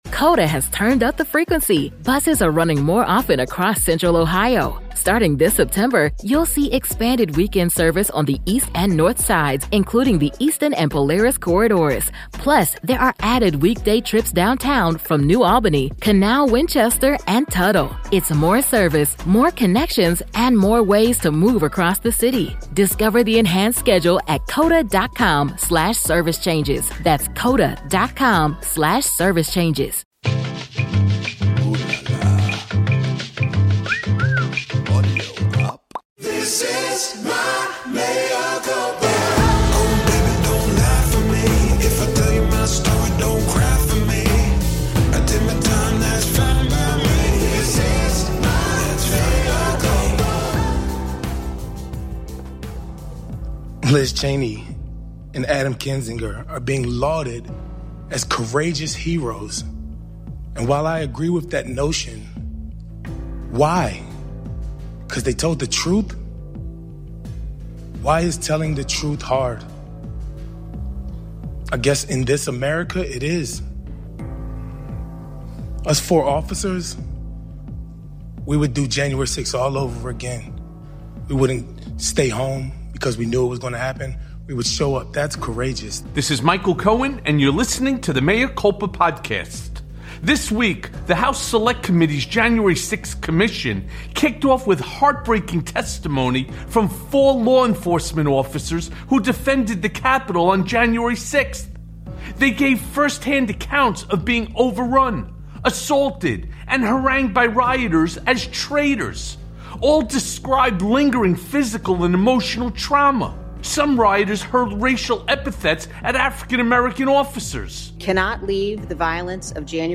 GOP Keeps Digging Their Own Grave on January 6th + A Conversation with Congressman Raja Krishnamoorthi
Plus, Mea Culpa welcomes Democratic Congressman Raja Krishnamoorthi to discuss the GOP moral failure on 1/6, Trump’s insanity and much, much more.